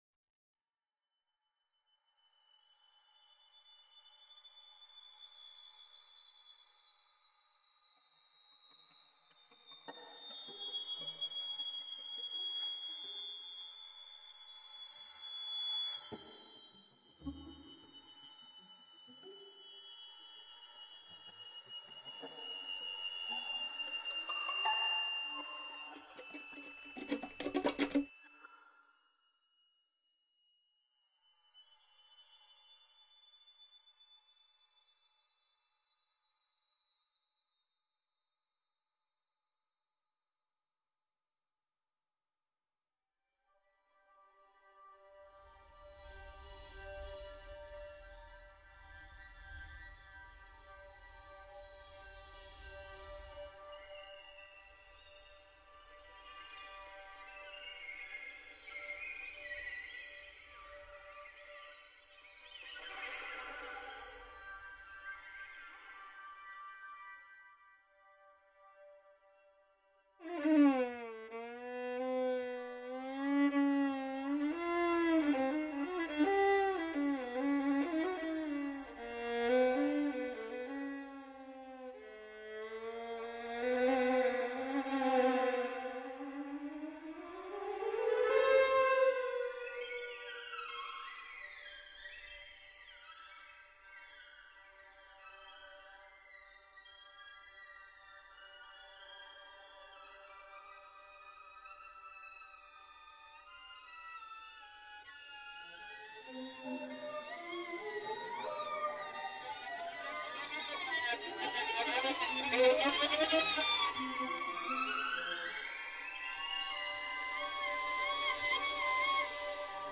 The sound of the Carpathian horns sends me into a world of myth and legend.
The natural harmonics of the violin gave me the background scene of the piece, to which I added various fragments of microtonal improvisation. I tried to unfold it as a chamber music piece. The general mood is pastoral, suggestive of wild mountain peaks, chamois and new cheese.
carpathianhorns.m4a